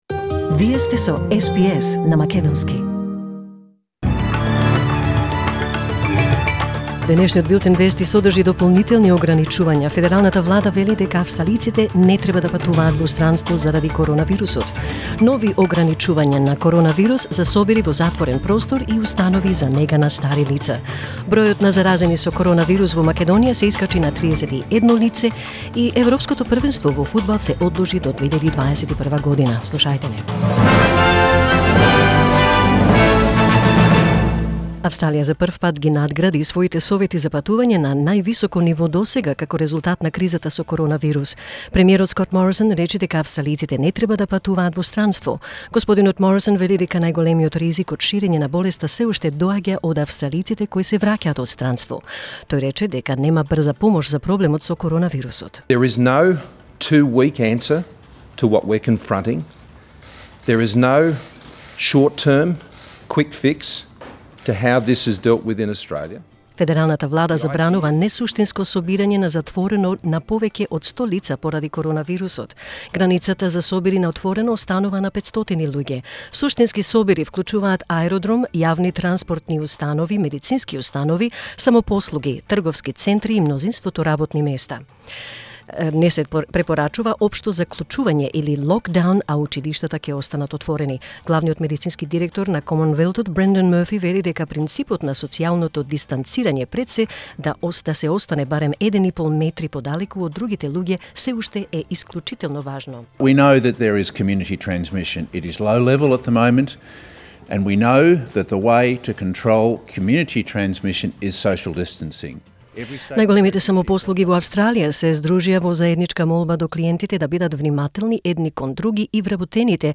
SBS News in Macedonian 18 March 2020